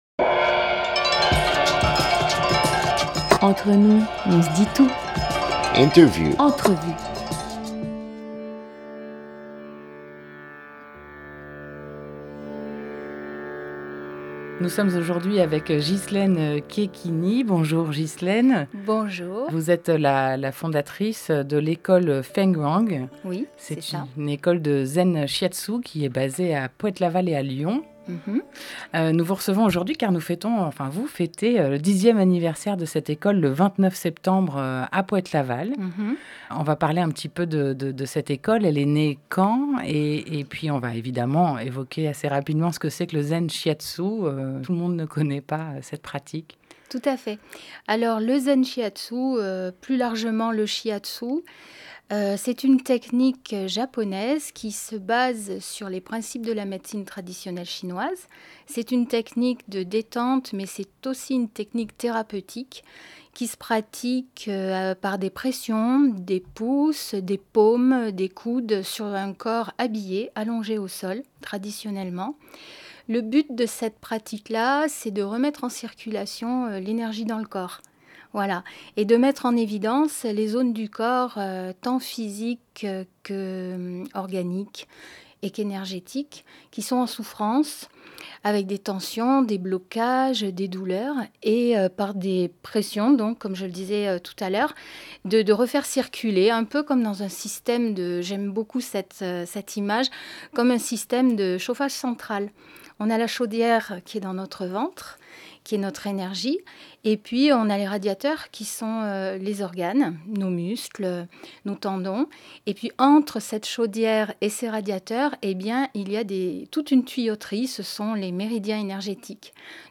27 septembre 2018 9:37 | Interview